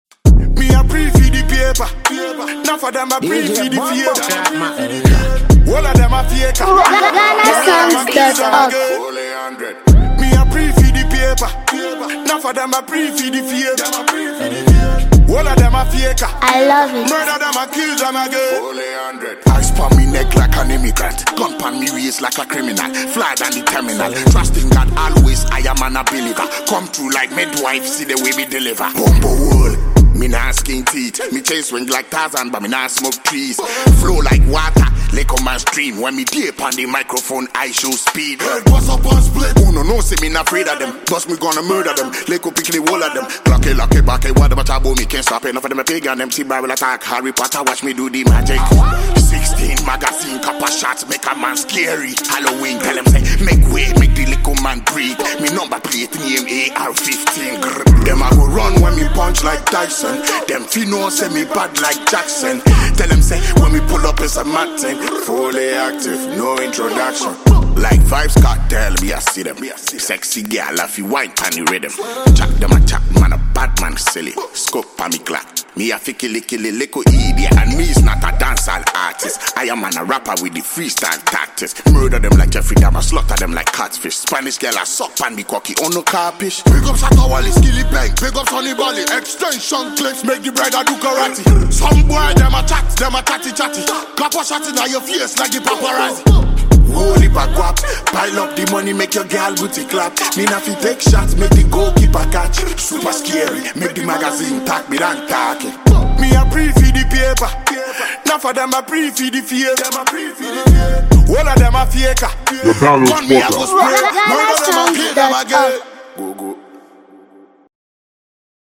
With aggressive delivery and commanding flow
giving the track a gritty and energetic feel.
heavy bass, vibrant percussion, and a fast tempo